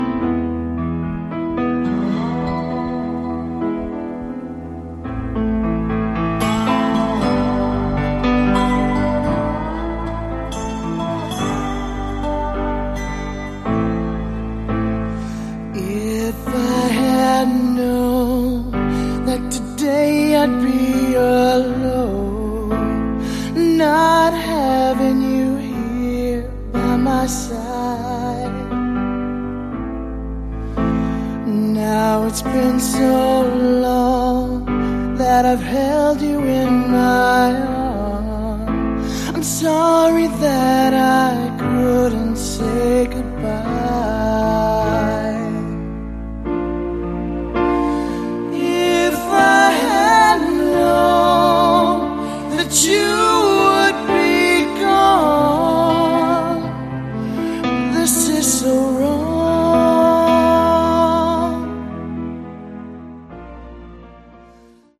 Category: Melodic Power Metal
vocals
guitars
keyboards
bass
drums